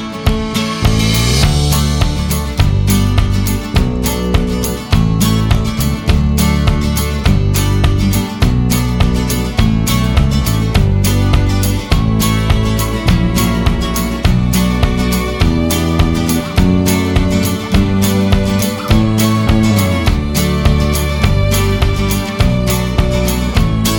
Minus All Guitars Pop (2010s) 3:52 Buy £1.50